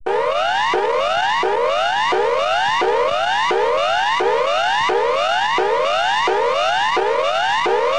Warning Signal